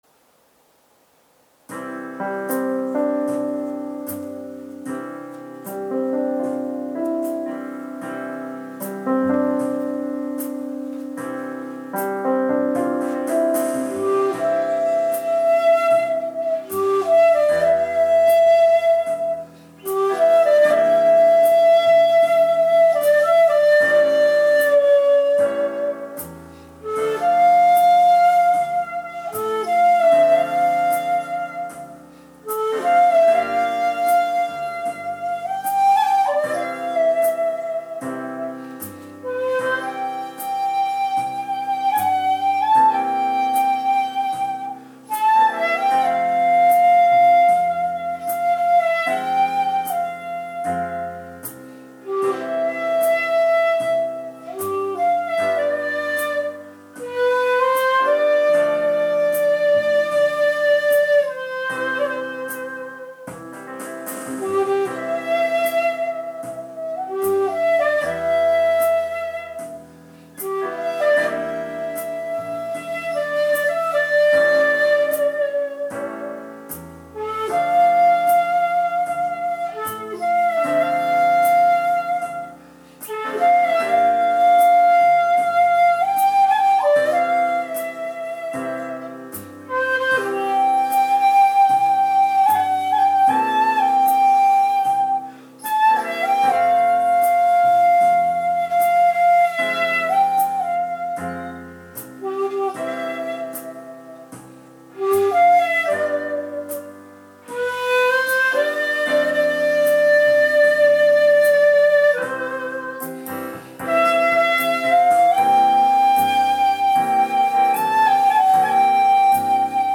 因みに、歌の曲は短い一尺六寸管で音程も無理のない地塗り尺八はやはり吹きやすいです。